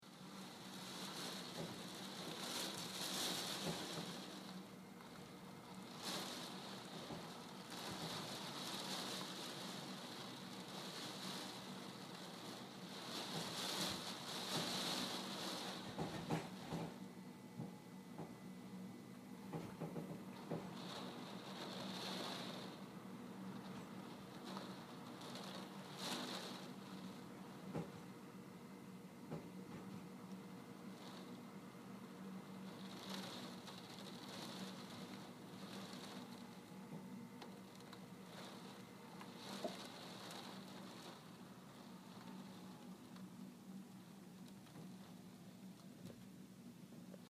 Field Recording – 4
Wind, Rain, Occasional car driving by
Rain.mp3